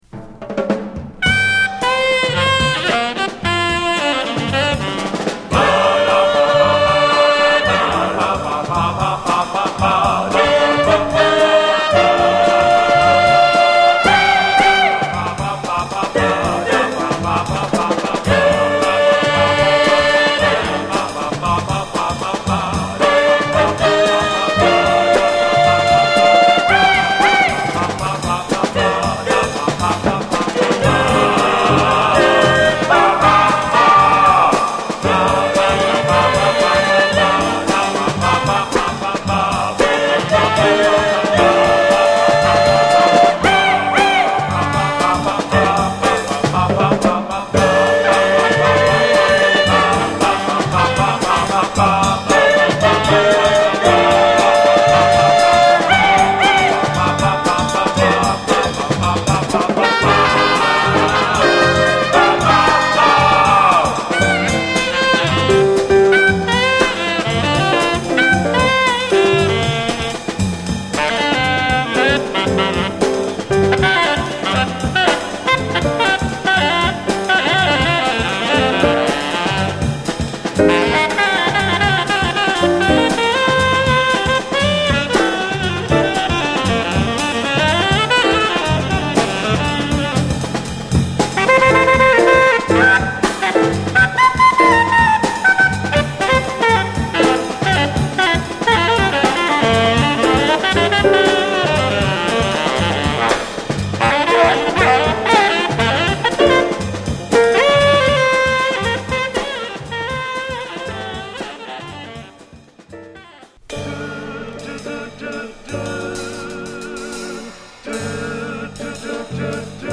クインテット編成の演奏に、全編に渡り男女混成のスキャット/コーラスをフィーチャーしたスピリチュアル感溢れる1枚で